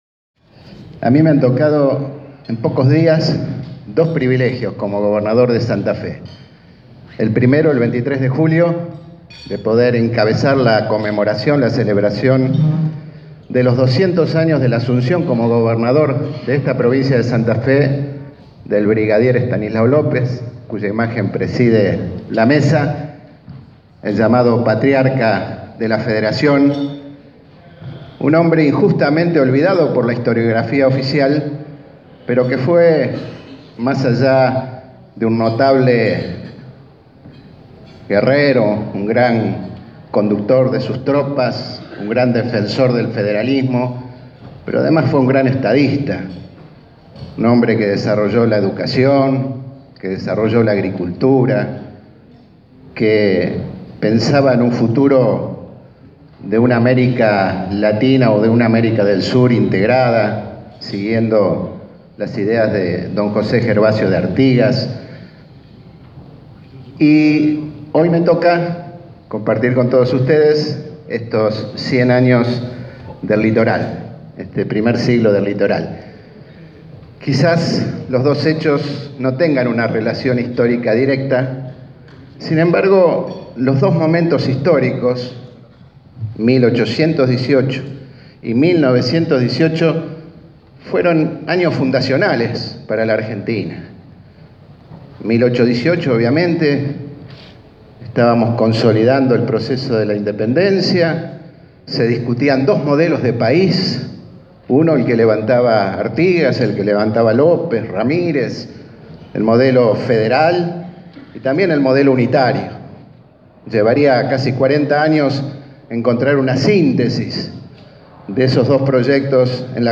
El gobernador Miguel Lifschitz participó este jueves, en el edificio de la Bolsa de Comercio de la ciudad de Santa Fe, de la cena durante la que se celebró el centenario del diario “El Litoral”.